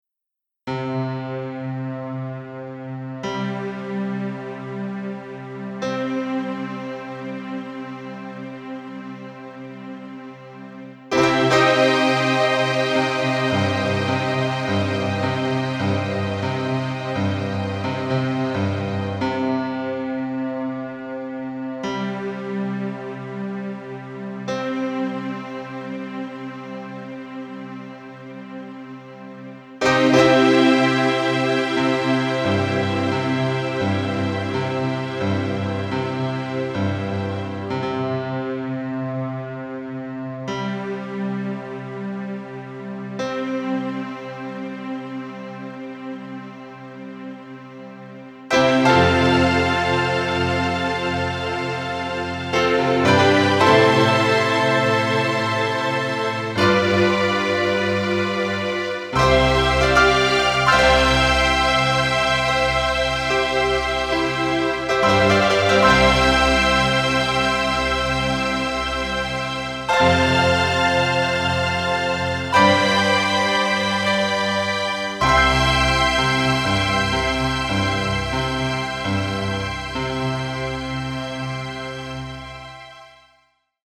ピアノアレンジによって新たな魅力を放ち、スマートフォンの着信音としてあなたの日常に新たな風を吹き込みます。